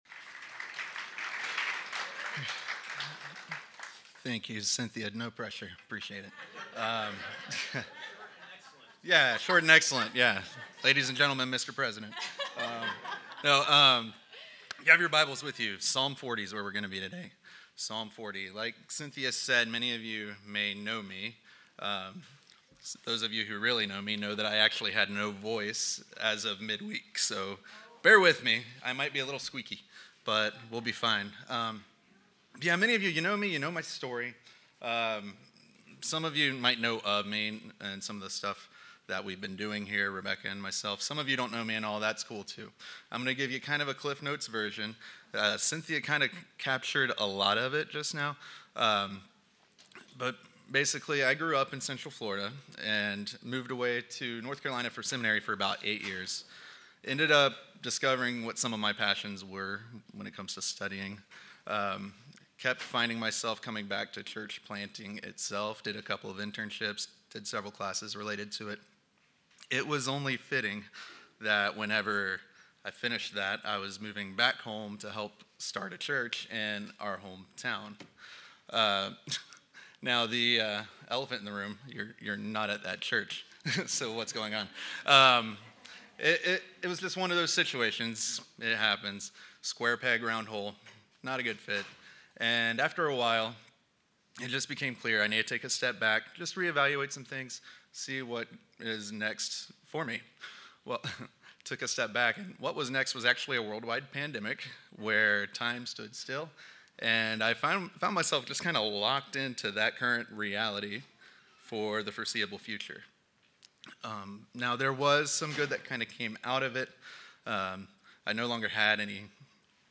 ________ Summer Preaching Schedule June 16